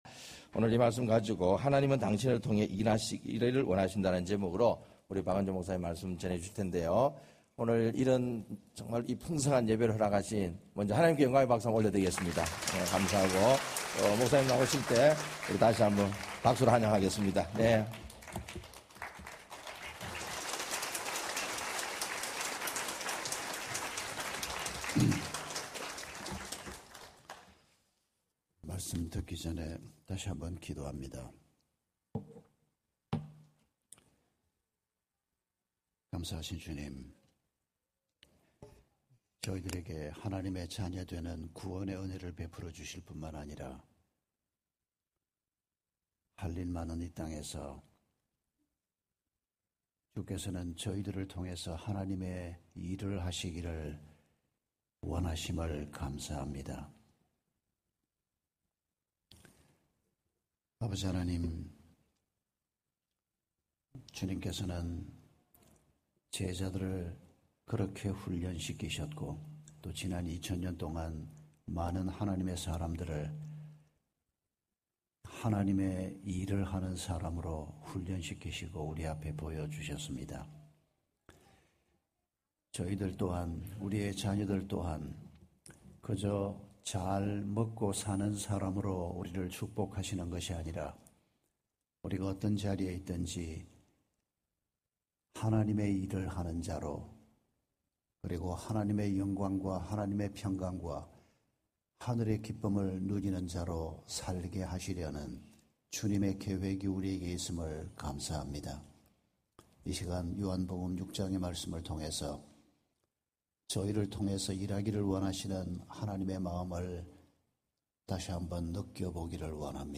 다운공동체교회 - 2022년 7월 3일 부흥회4(요한복음 6장 1절~14절)